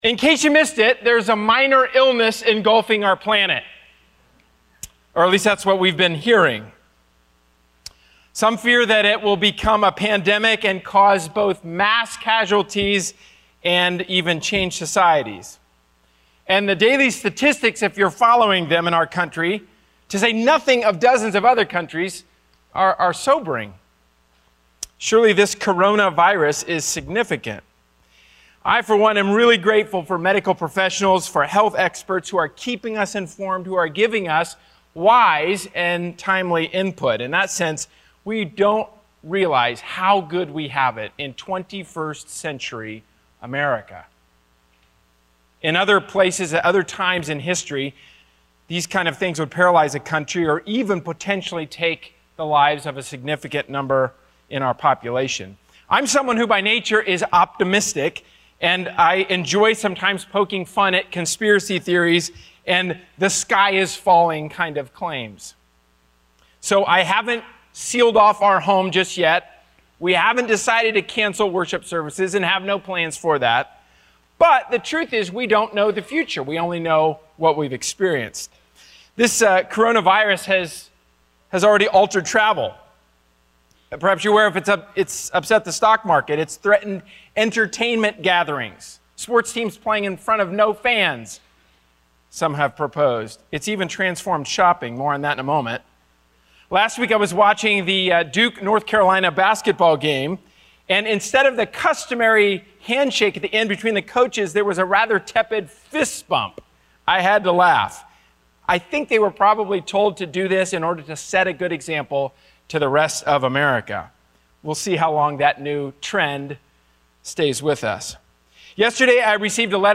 A sermon from the series "Heaven, Help the Home!." Parenting calls us to connect our children with the ultimate Parent in whom is life.